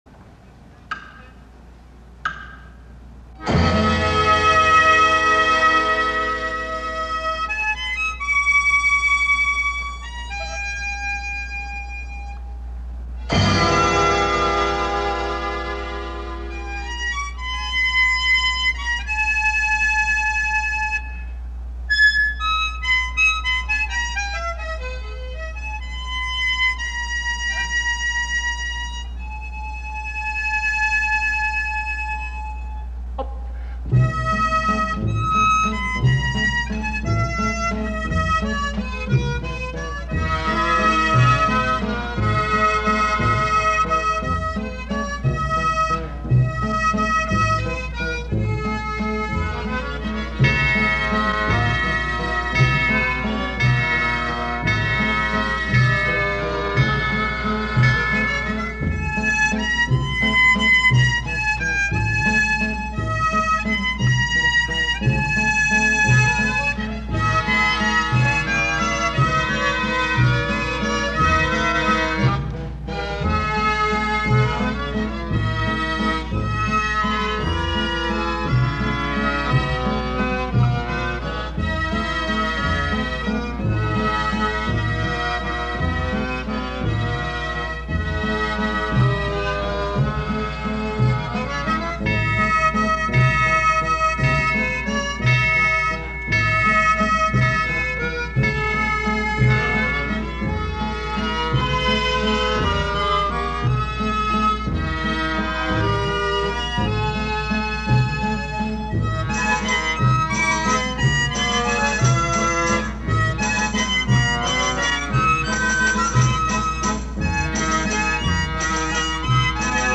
Blue Bird Band
National Sanatorium Nagashima Aiseien, Okayama